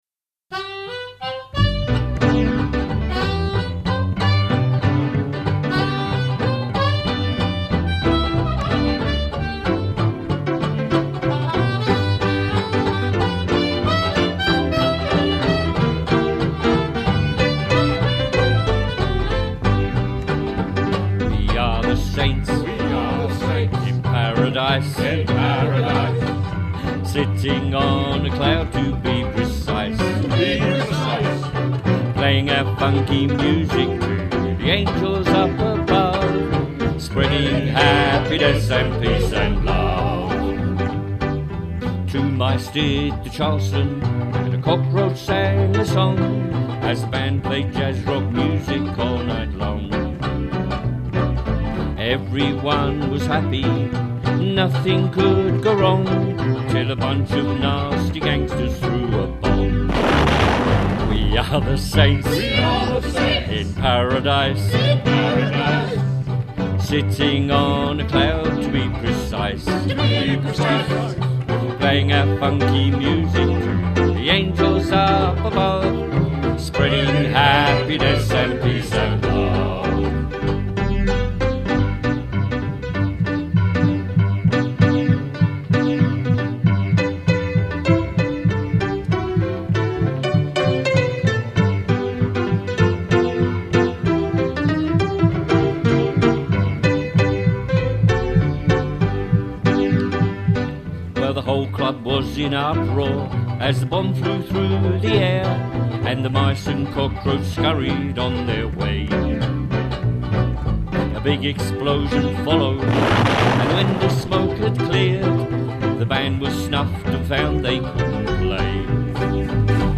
"Saints In Paradise" Recording Session
banjo
double bass
keyboard
soprano saxophone
The vocals were added after we had listened to, and were happy with this take.
with the digital recording studio